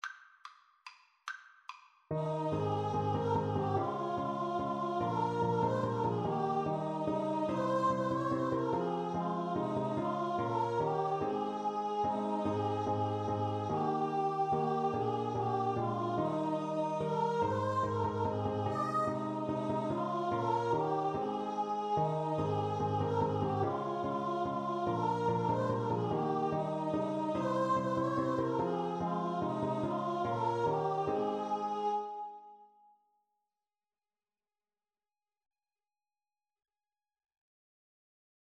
Free Sheet music for Choir (SATB)
3/4 (View more 3/4 Music)